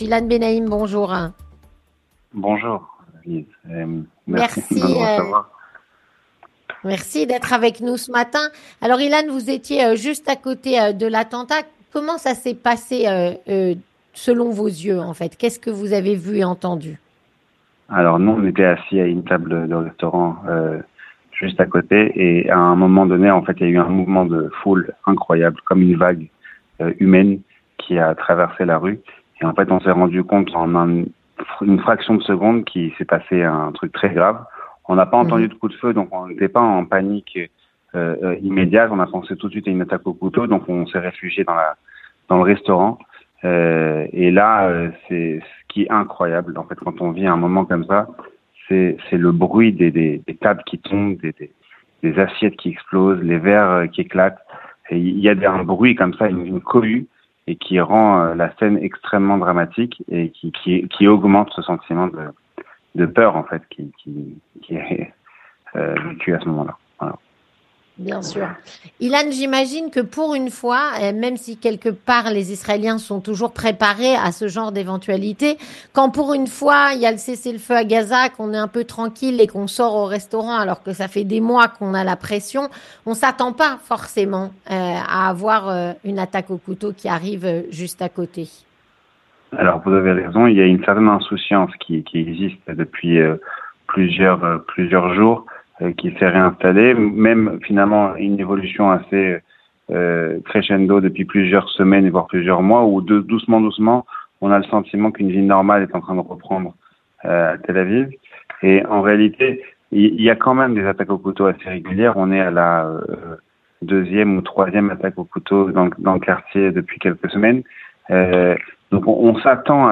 Il témoigne.